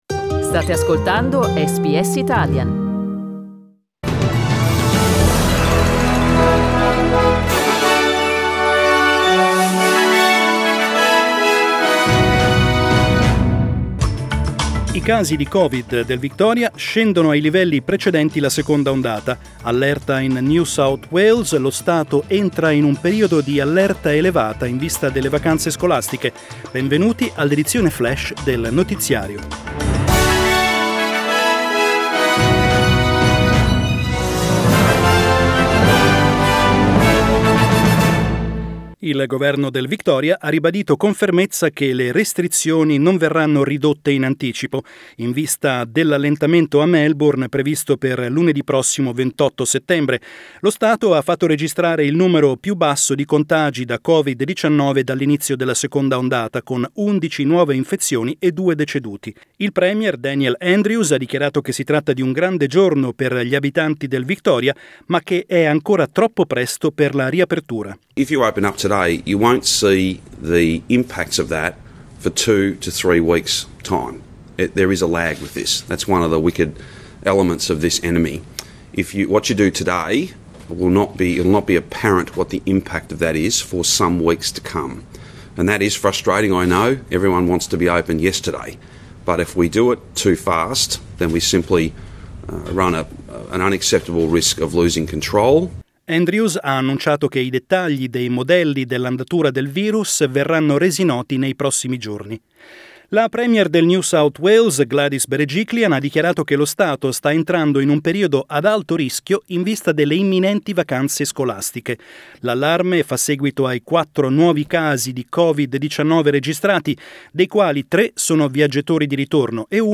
Our latest news update (in Italian).